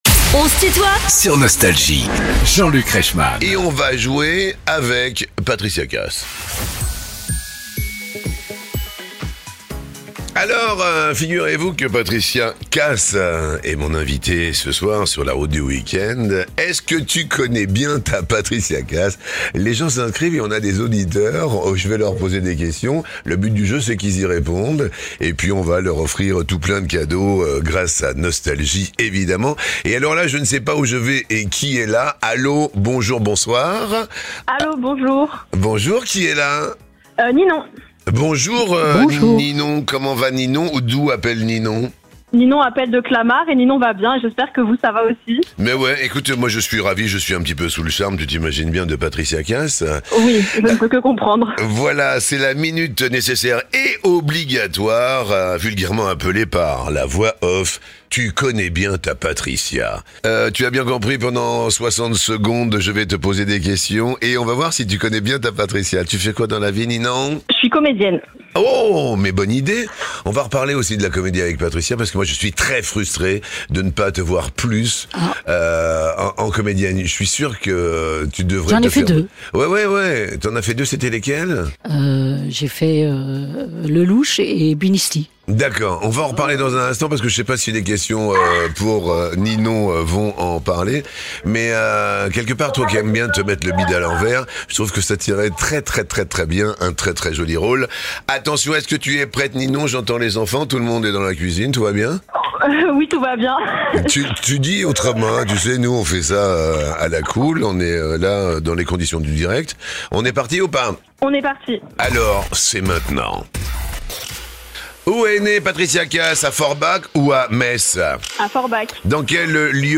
Patricia Kaas est invitée de "On se tutoie ?..." avec Jean-Luc Reichmann
Erweiterte Suche Tu connais bien ta Patricia Kaas ? 16 Minuten 14.58 MB Podcast Podcaster Les interviews Les plus grands artistes sont en interview sur Nostalgie.